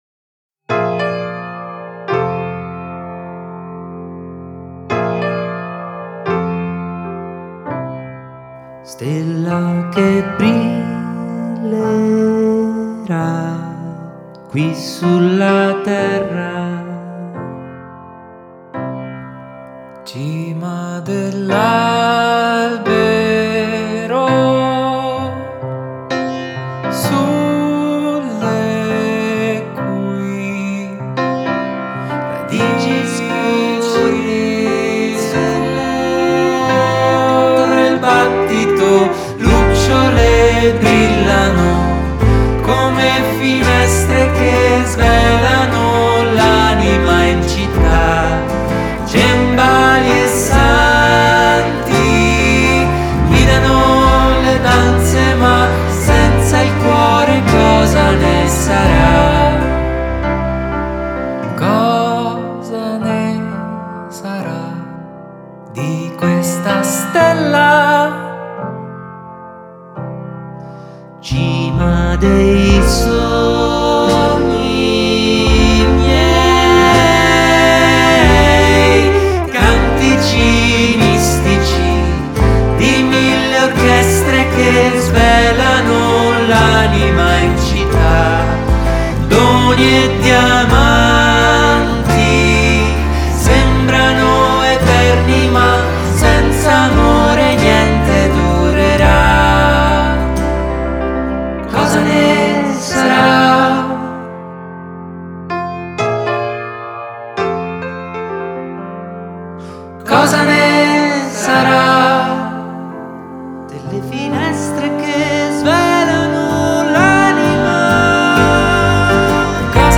è un chitarrista.